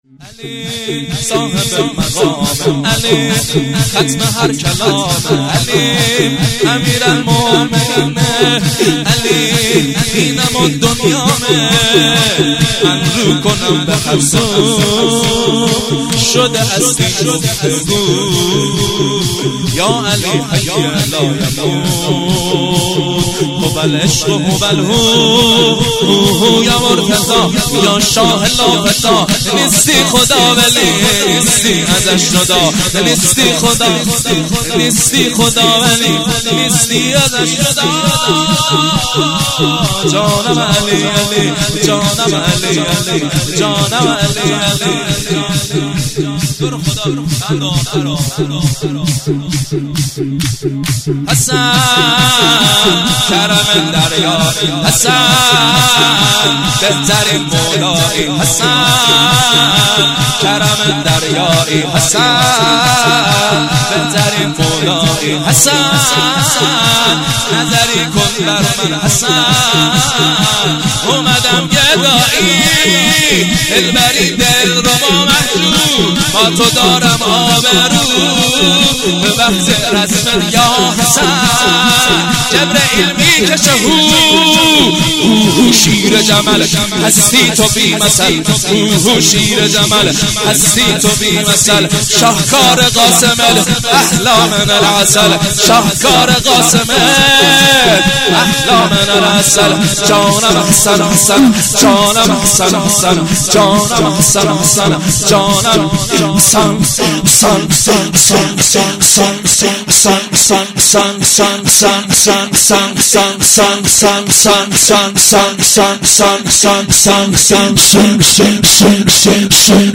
شور - علی صاحب مقامه
جشن مبعث - جمعه24 فروردین 1397